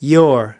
12.Your /jʊr/: của bạn, của các bạn